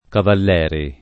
Cavalleri [ kavall $ ri ] cogn.